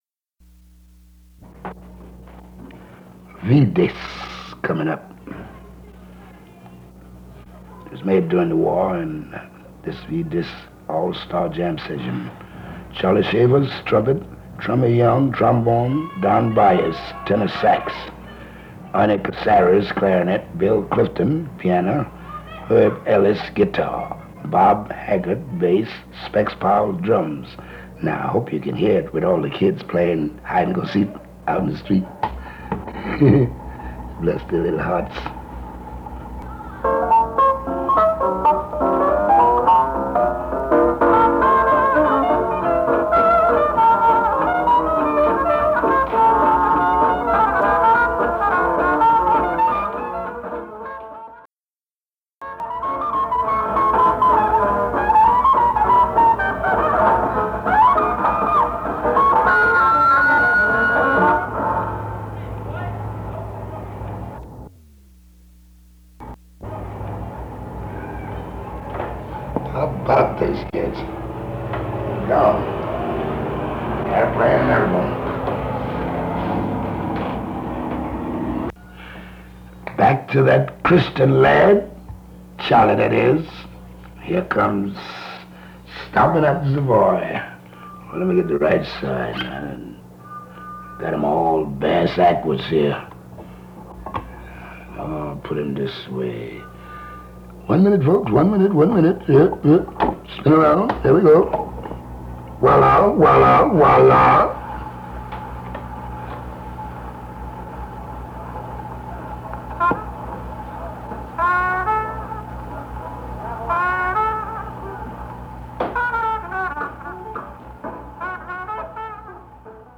Sure enough, as Armstrong announces “V Disc Time,” the children in the neighborhood can clearly be heard playing outside his open window.
We have edited this clip to also include Armstrong’s reactions to a number of records, including some by the pioneer of the electric guitar Charlie Christian (erroneously but humorously listed as “Charlie Christmas” in the above handwritten notes!).